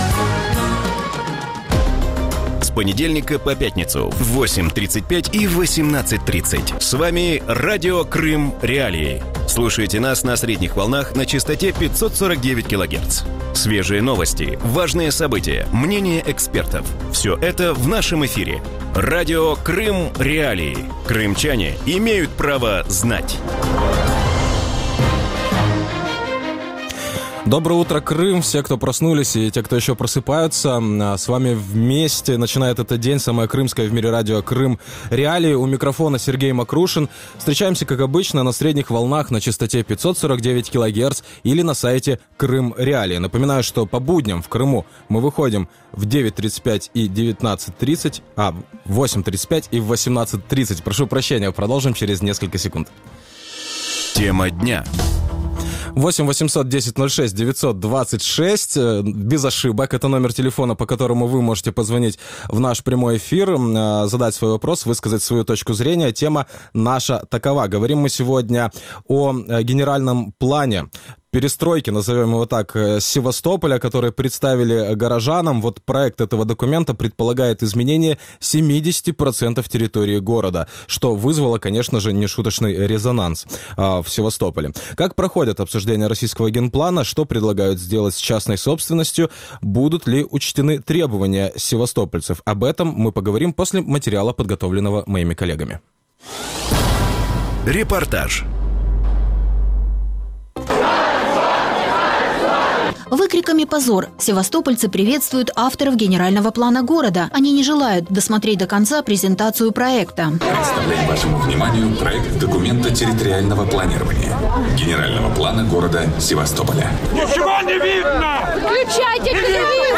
Утром в эфире Радио Крым.Реалии говорят о российском генеральном плане перестройки Севастополя. Представленный горожанам проект предполагает изменение 70% территории города. Как проходят обсуждения российского генплана в Севастополе?